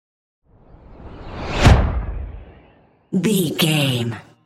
Dramatic whoosh to hit airy trailer
Sound Effects
Atonal
dark
high tech
intense
tension
woosh to hit